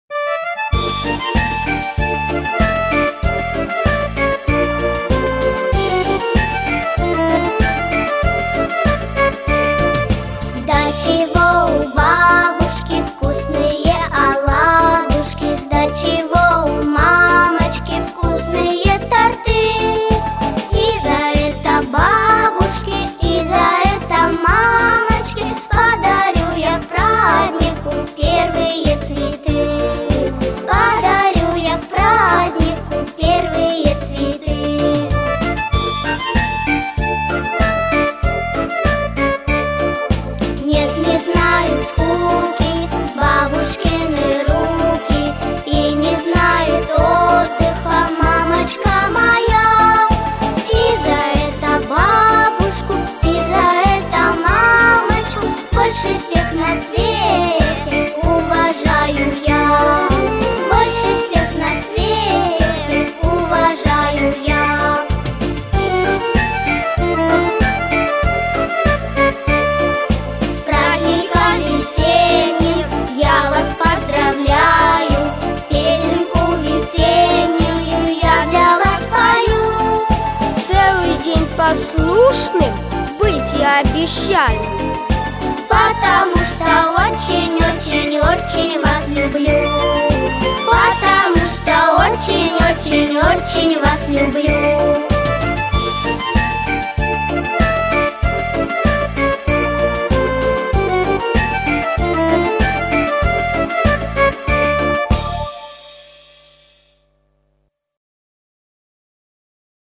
Плюсовий запис
Гарно записано!